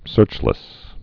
(sûrchlĭs)